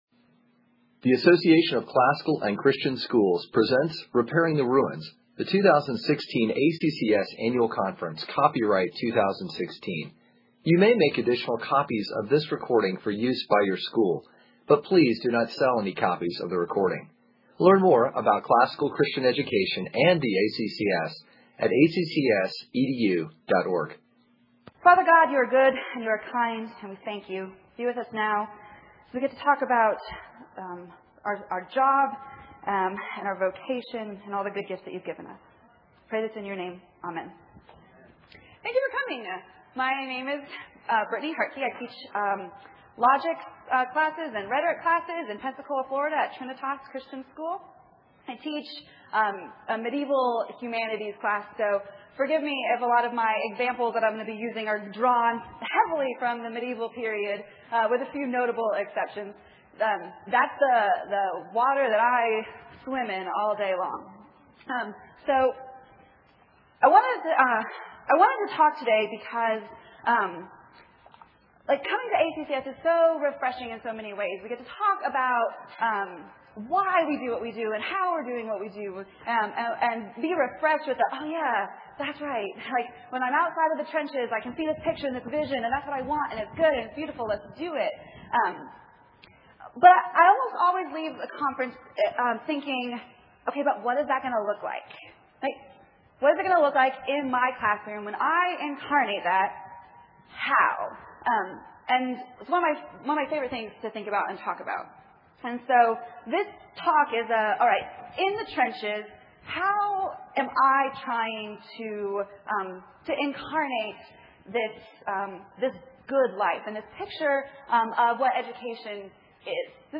2016 Foundations Talk | 1:04:32 | All Grade Levels, General Classroom